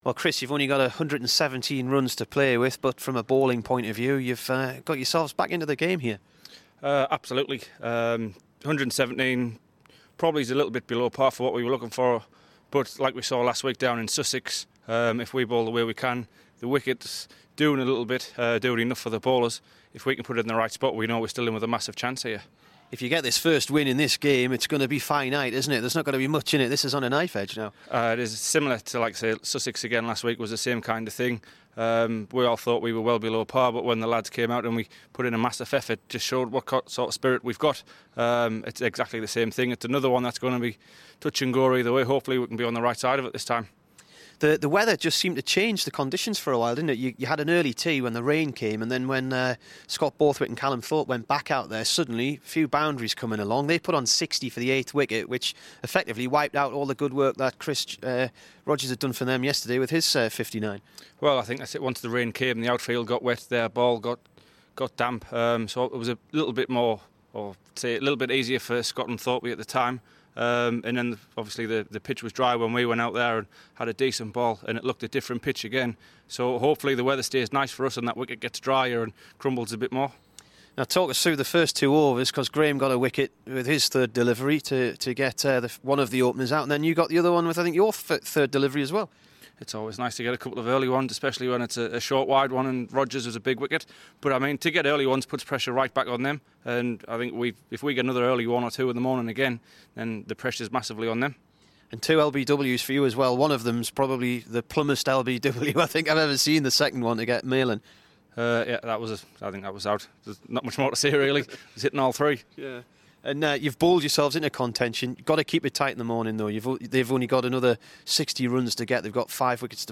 TALKS TO BBC NEWCASTLE